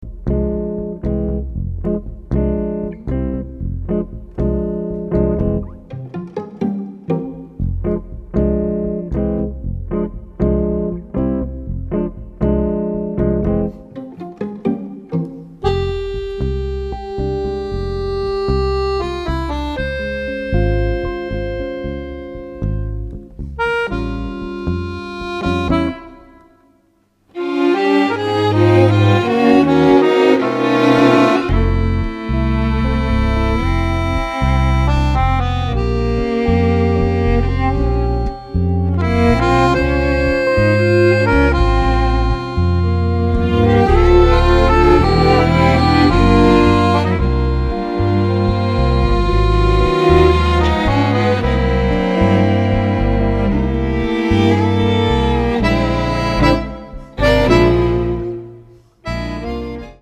Accordion
chitarra elettrica
basso elettrico ed acustico
drums and percussion
violin
Violino, voce
viola
violoncello Cello